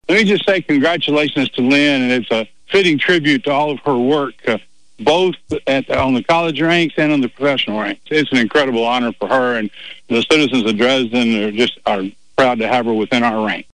Mayor Maddox praises Ms. Dunn for her accomplishments.